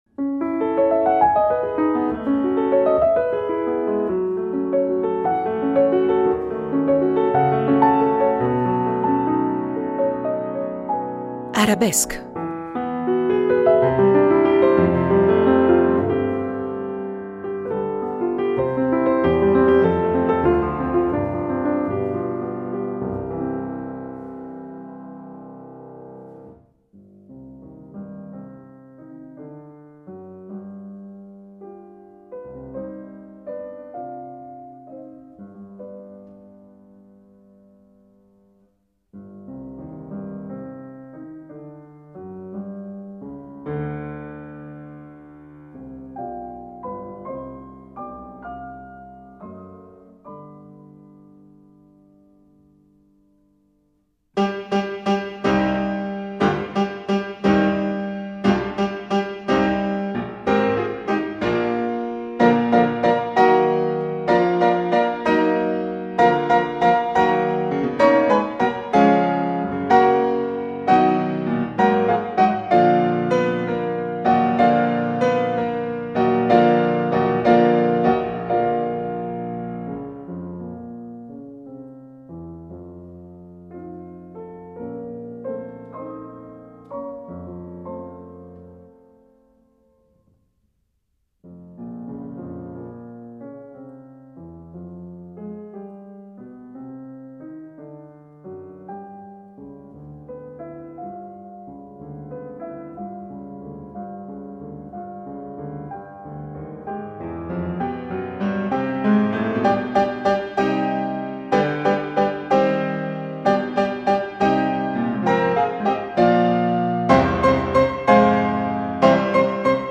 Questa è la terza parte della loro conversazione.